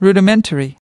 واژه سی و هشتم) rudimentary /rōō´dǝ men´tǝrē/ adj.